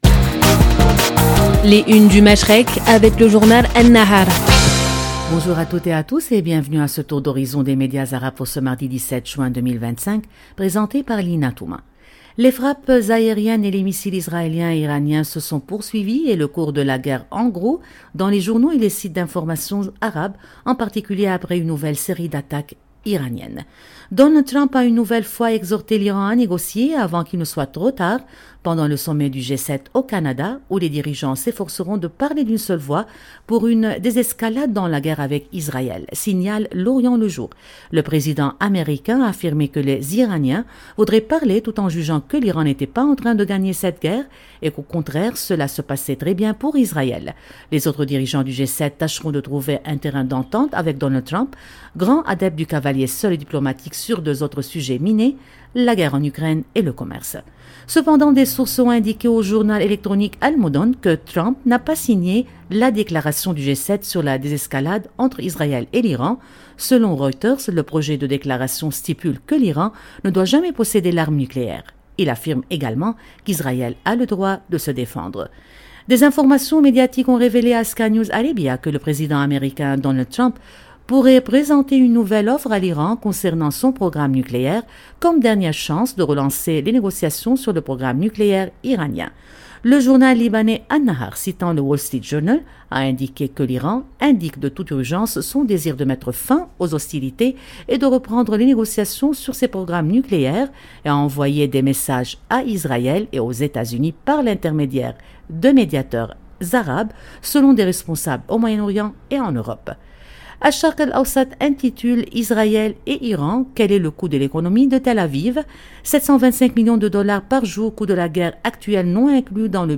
Radio Orient vous présente, en partenariat avec le journal libanais An-Nahar , une revue de presse complète des grands titres du Moyen-Orient et du Golfe. À travers des regards croisés et des analyses approfondies, cette chronique quotidienne offre un décryptage rigoureux de l’actualité politique, sociale et économique de la région, en donnant la parole aux médias arabes pour mieux comprendre les enjeux qui façonnent le Machrek. 0:00 4 min 2 sec